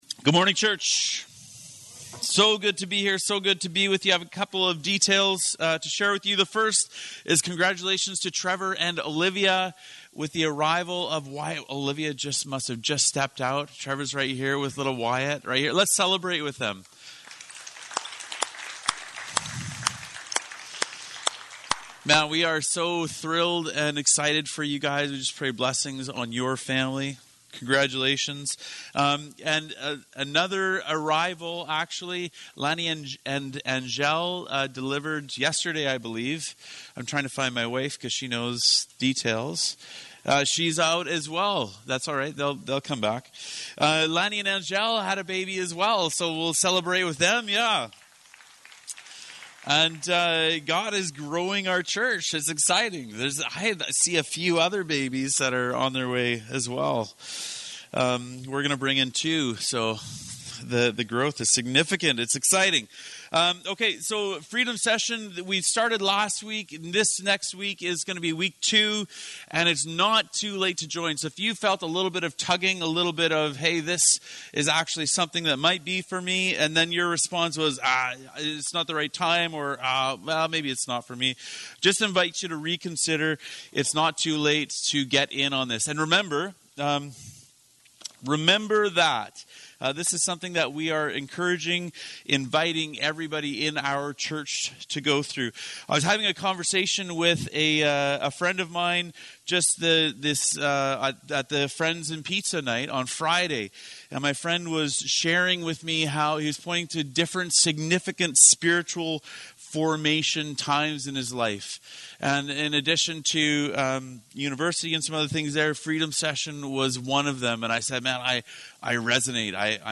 Sermons | Crossroads Community Church of the Christian and Missionary Alliance in Canada